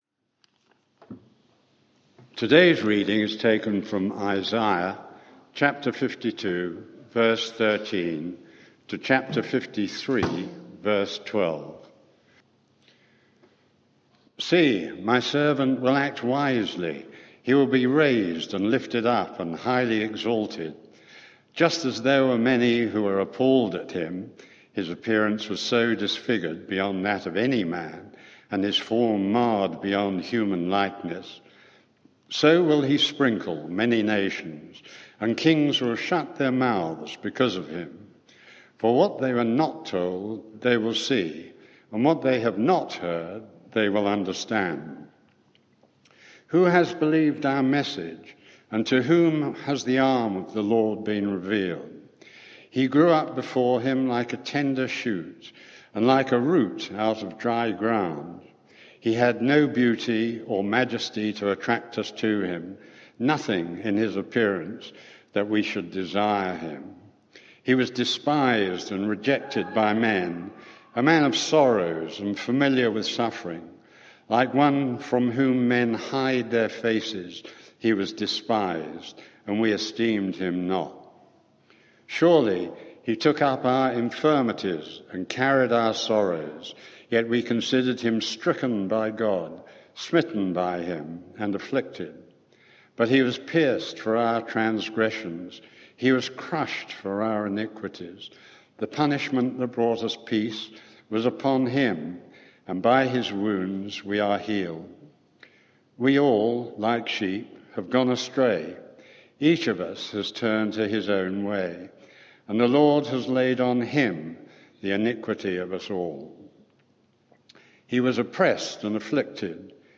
Media for 11am Service on Sun 27th Mar 2022 11:00 Speaker
Series: The Servant King Theme: The Suffering Servant Sermon (audio)